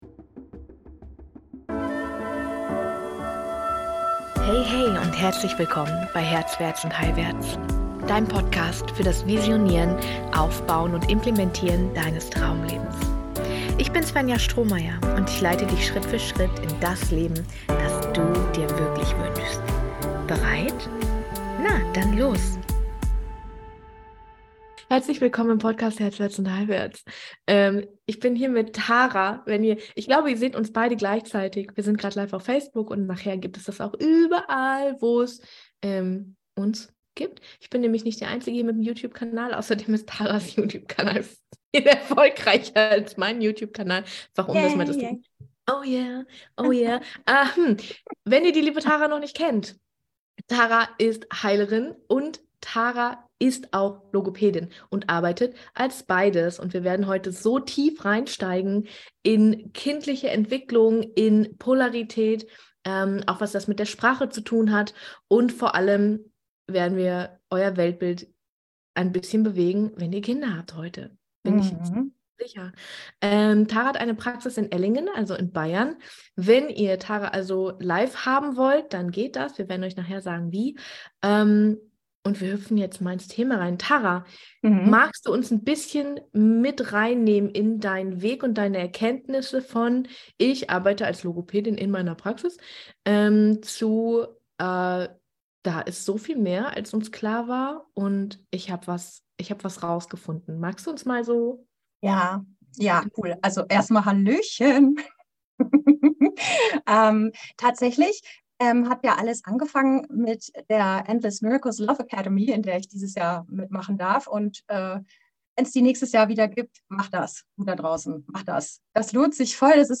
In diesem inspirierenden Gespräch sprechen wir über frühkindliche Erziehung, unbewusste Verhaltensweisen und über die Polarität in uns und in der Beziehung zu unseren Partner*innen und Kindern. Was das alles mit der Sprache zu tun hat und was eine gute Mutter ausmacht - das und noch viel mehr erfahrt ihr in dieser Folge.